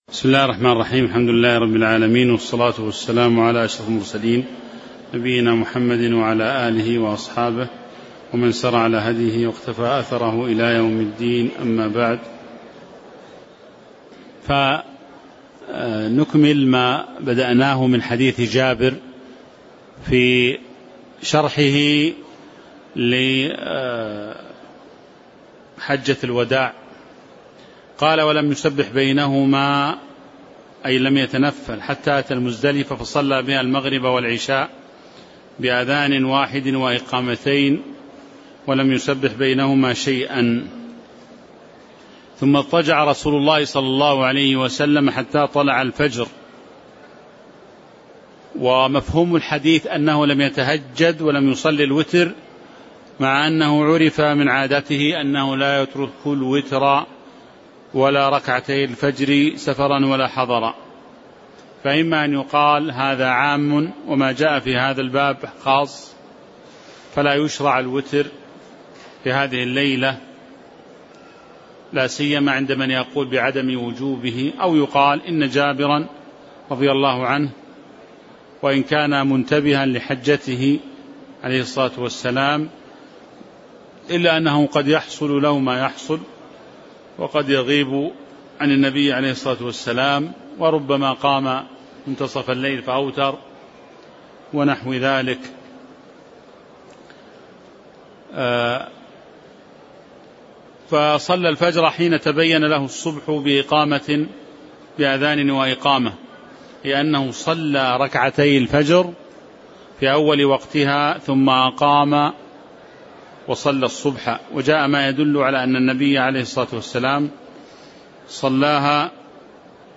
تاريخ النشر ٣ ذو القعدة ١٤٤٦ هـ المكان: المسجد النبوي الشيخ